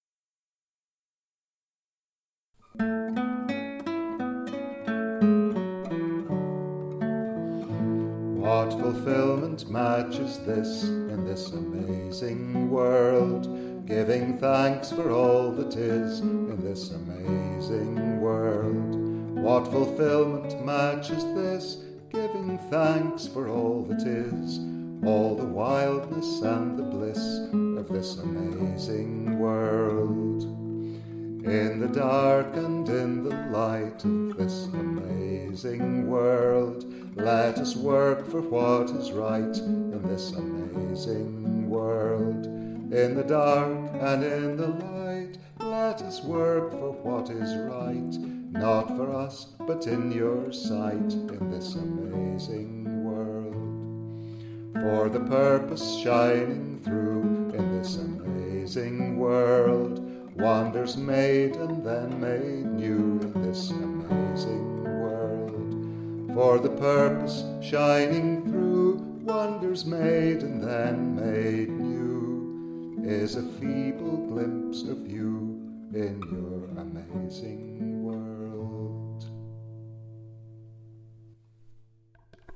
The tune is 'The shearin's no for you', a Scots traditional song that takes some harsh realities head-on.